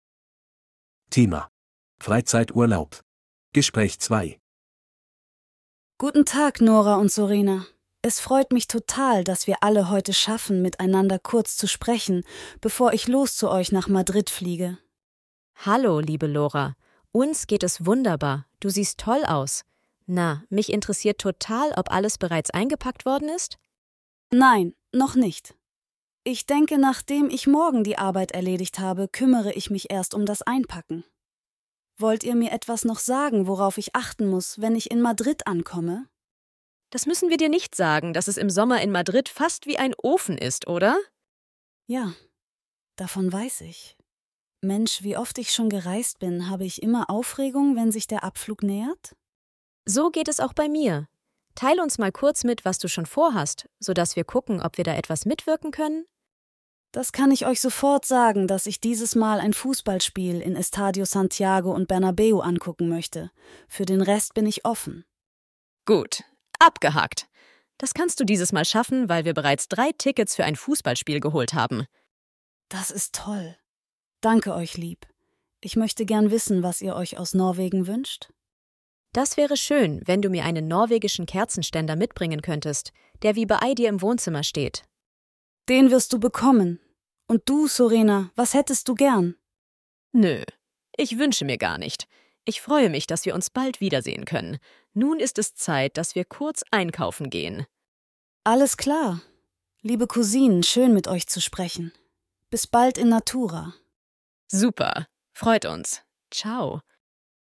Audio text conversation 2:
A2-Kostenlose-R-Uebungssatz-7-Freizeit-Urlaub-Gespraech-2.mp3